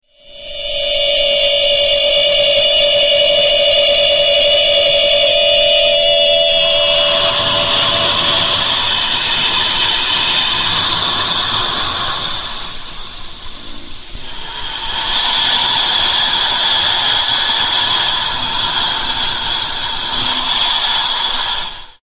ghost-noise_14214.mp3